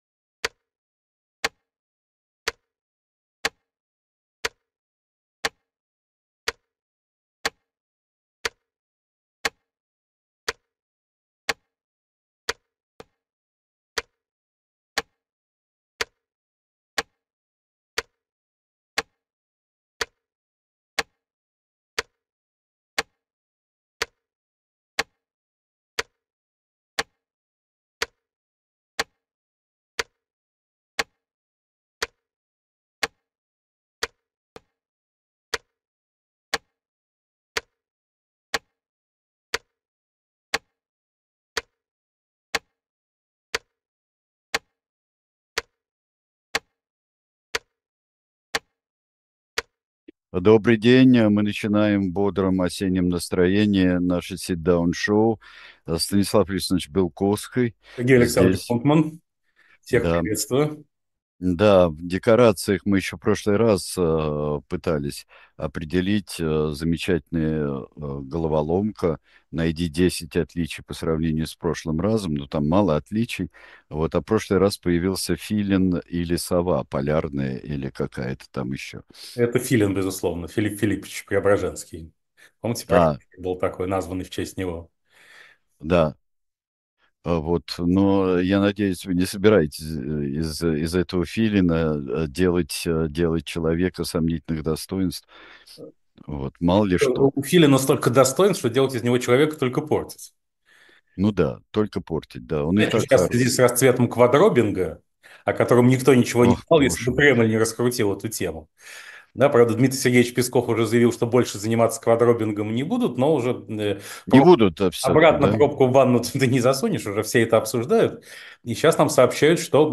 Эфир ведет Сергей Бунтман.